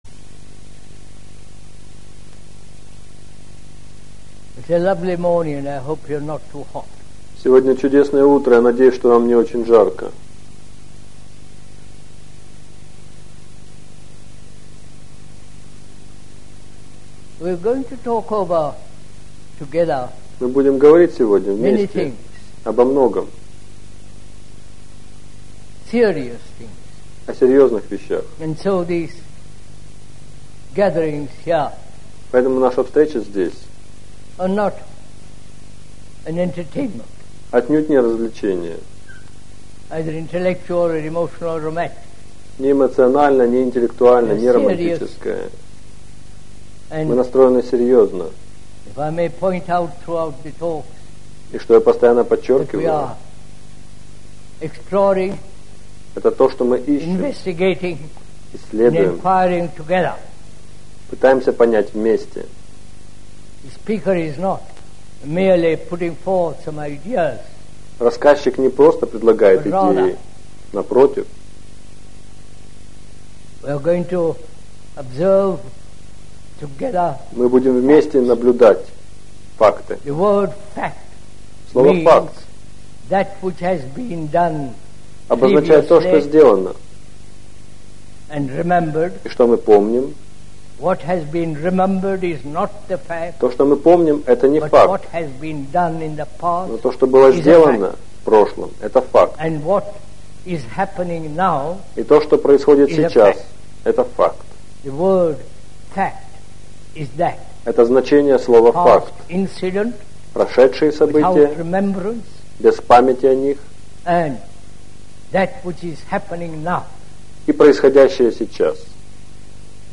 Беседы Кришнамурти, записанные в 1983 году в г.Саанен (Швейцария).